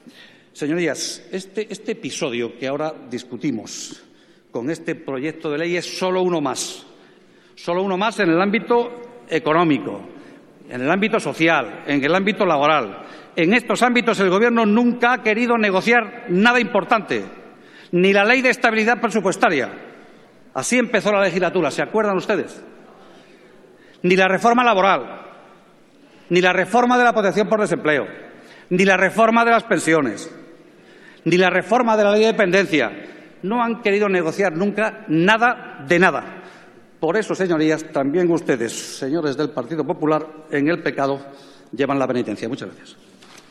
Valeriano Gómez en el debate sobre el proyecto de ley de ordenación, supervisión y solvencia de las entidades bancarias. 26/04/2014